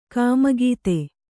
♪ kāmagīte